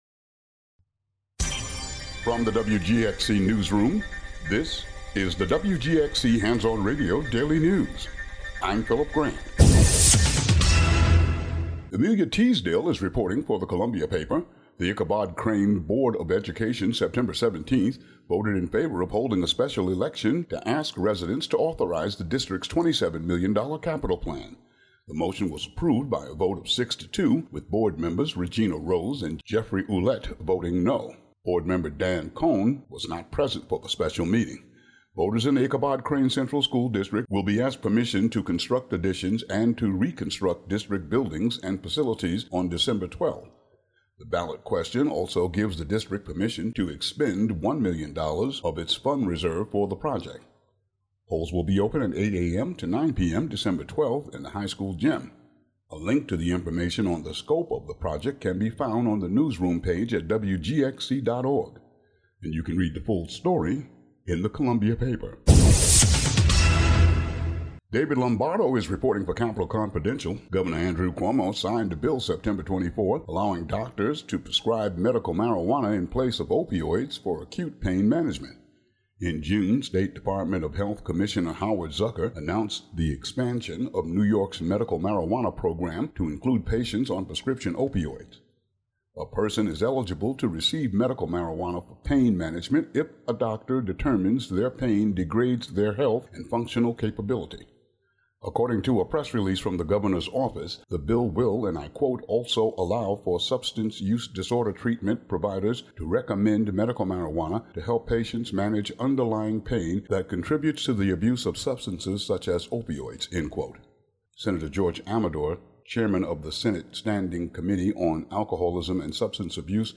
Daily headlines for WGXC.